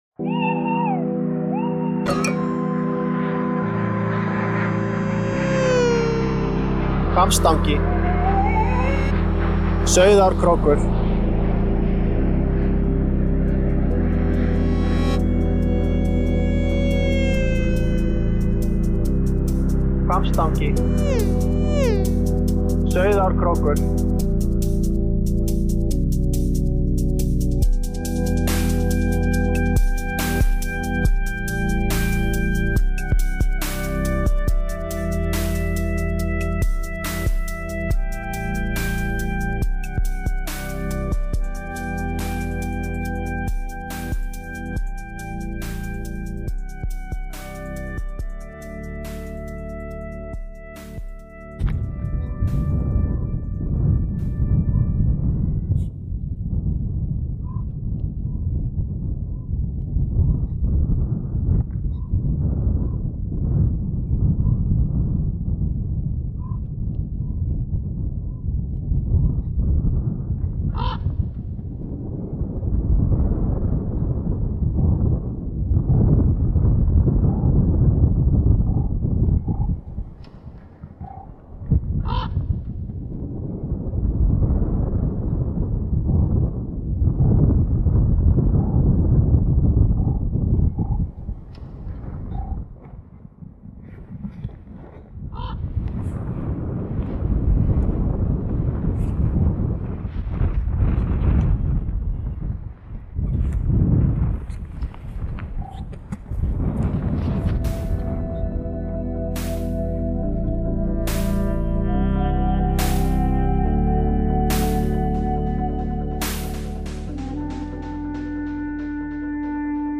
Un vent étourdissant qui ne gêne pas les corbeaux islandais